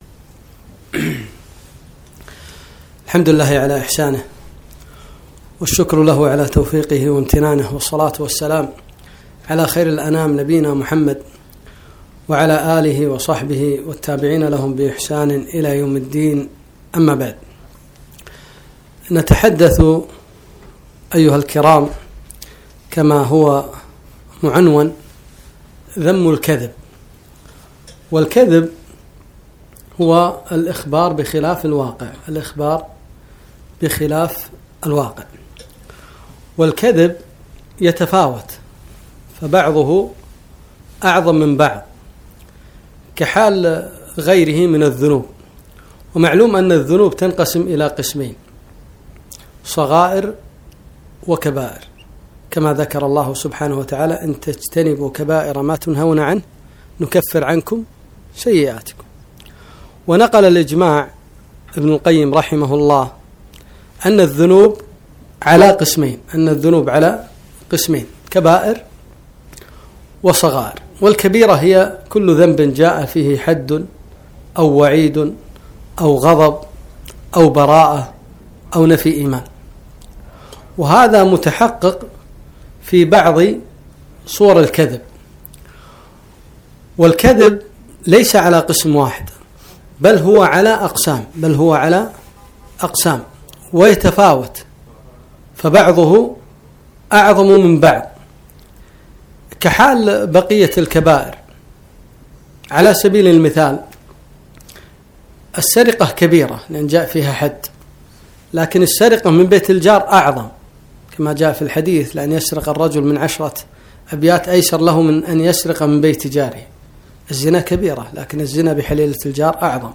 كلمة - آفة الكذب